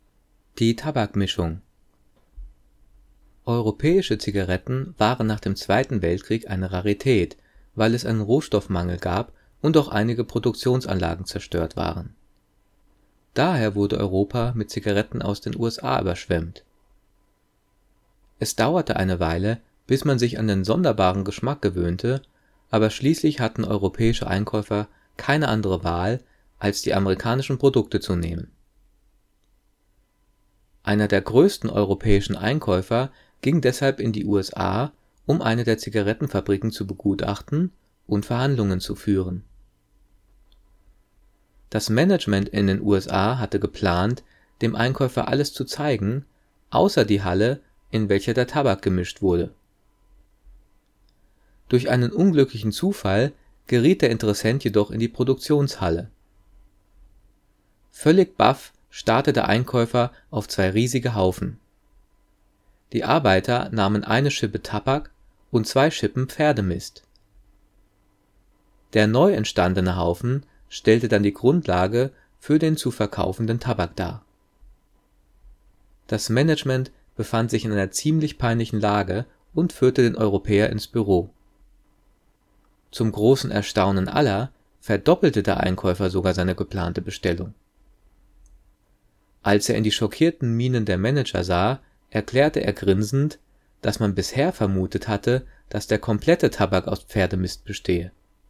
Gelesen:
gelesen-die-tabakmischung.mp3